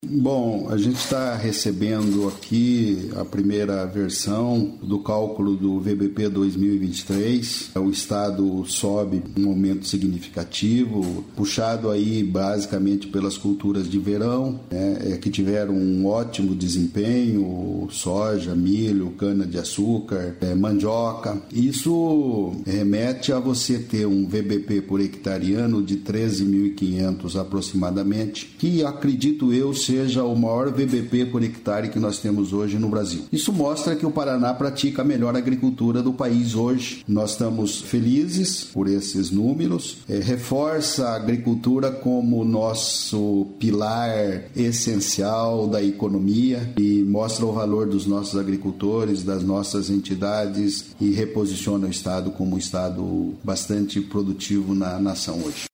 Sonora do secretário de Agricultura e Abastecimento, Natalino Avance, sobre o VBP Agropecuário do Paraná